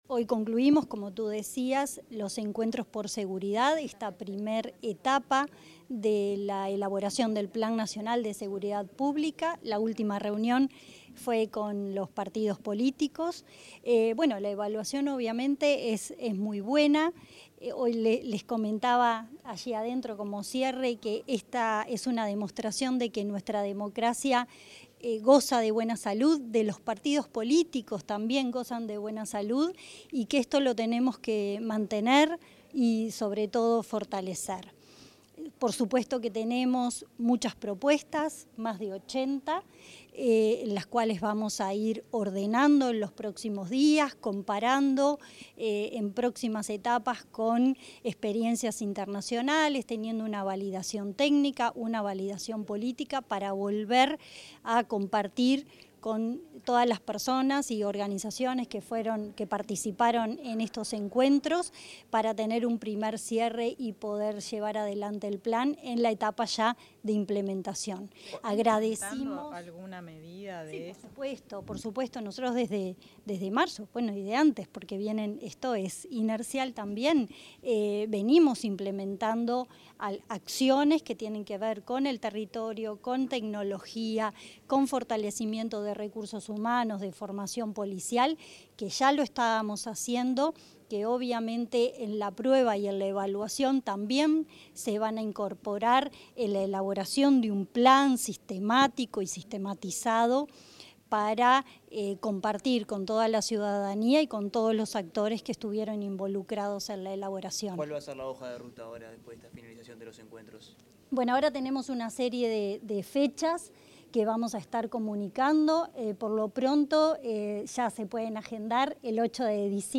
Declaraciones de la ministra interina de Interior, Gabriela Valverde
Declaraciones de la ministra interina de Interior, Gabriela Valverde 14/11/2025 Compartir Facebook X Copiar enlace WhatsApp LinkedIn Este viernes 14, finalizó la primera etapa de la elaboración del Plan Nacional de Seguridad Pública 2025-2035, para el que 80 instituciones públicas y privadas presentaron 80 propuestas sobre siete ejes estratégicos. Tras el encuentro, la ministra interina de Interior, Gabriela Valverde, diálogo con los medios de prensa.